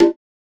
Batmans DarkSoul Perc 3.wav